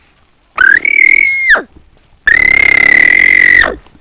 동물소리흉내02.mp3